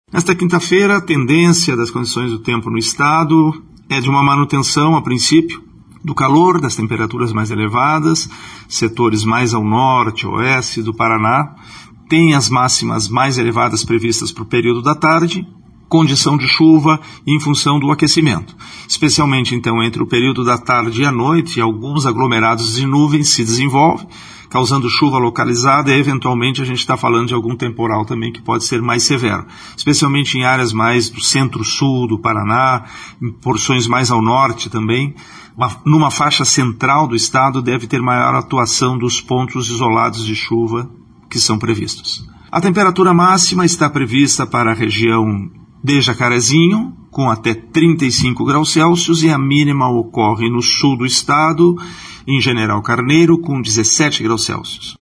[Sonora]